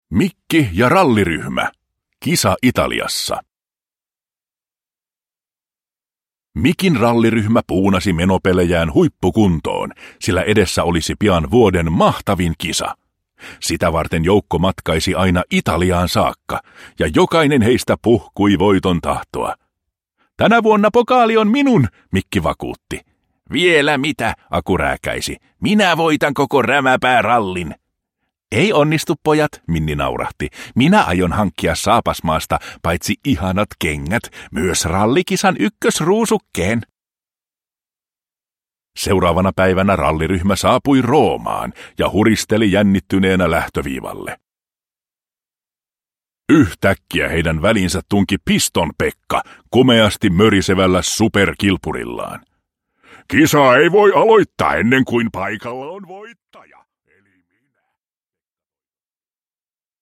Mikki ja ralliryhmä. Kisa Italiassa – Ljudbok – Laddas ner